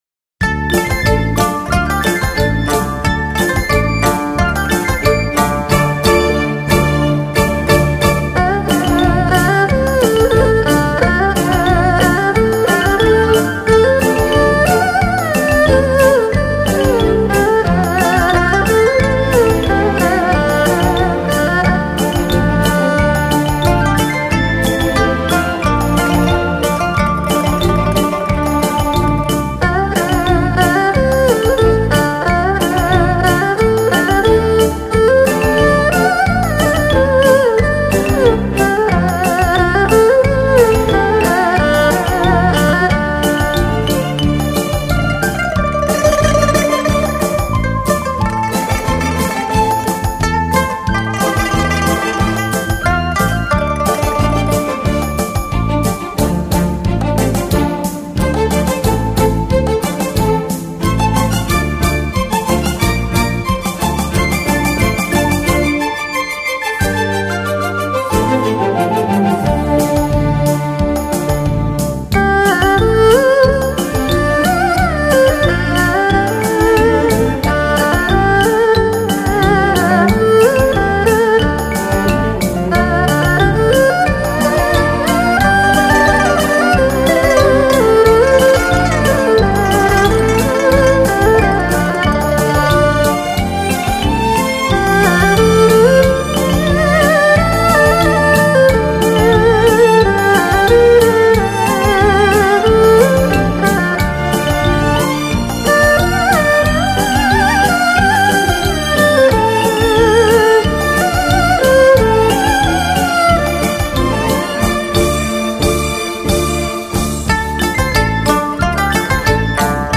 三维环绕HI-FI多音色高临场天碟
类型: 汽车音乐
二胡